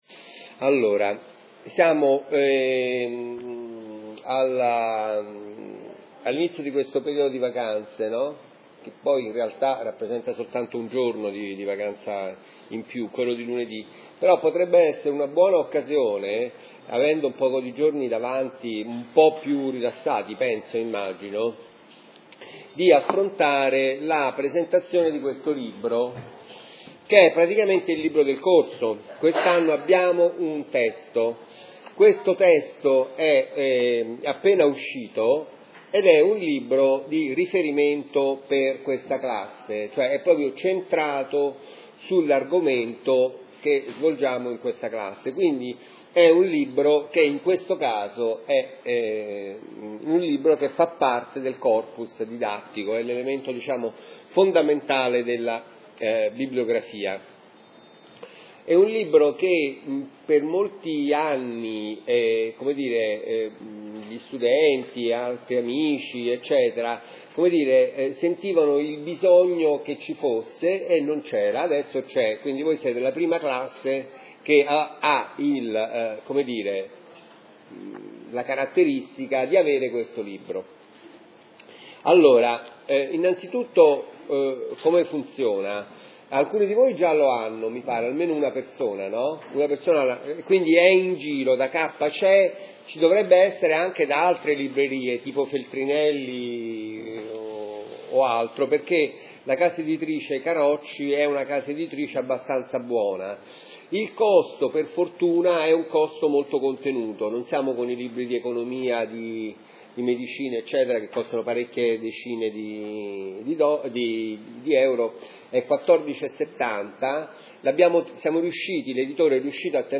Lezione 5